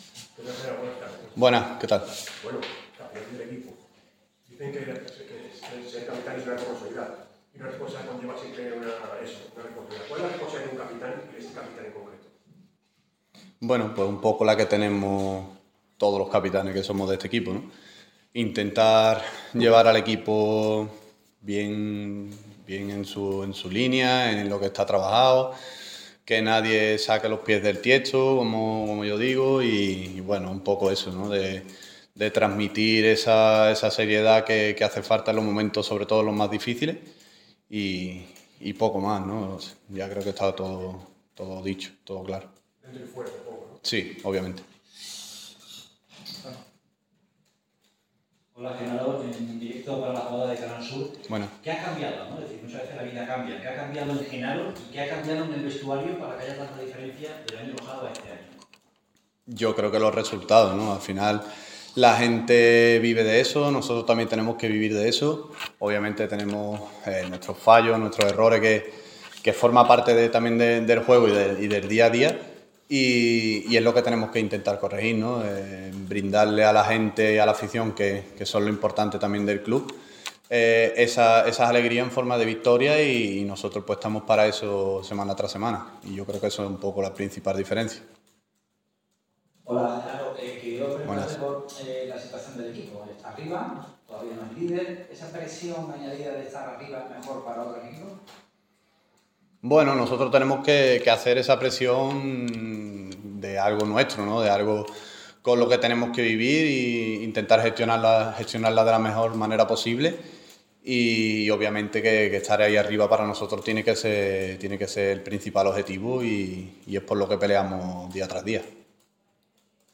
Miércoles de micrófono en La Rosaleda.